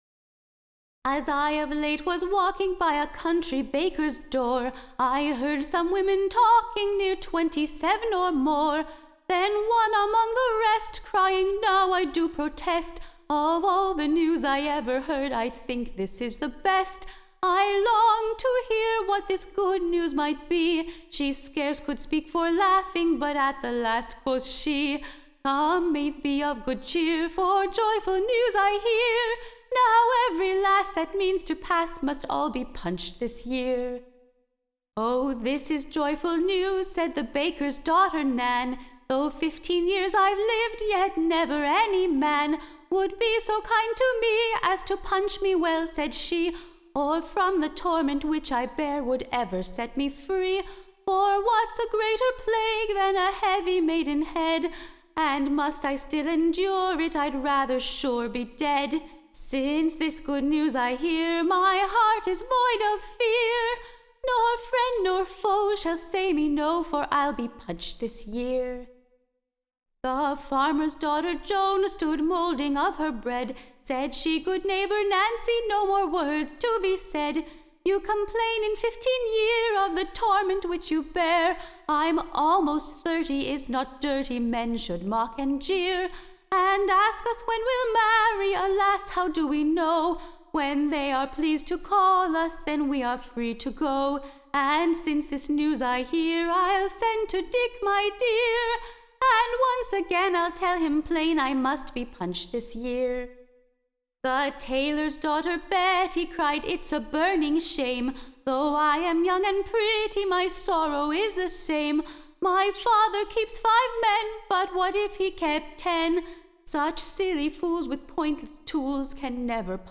EBBA 22348 - UCSB English Broadside Ballad Archive